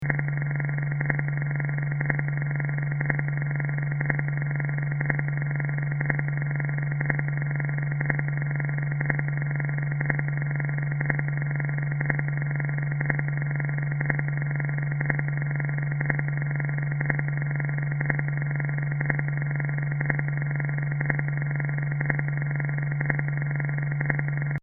Accordion
Audio musical
sound art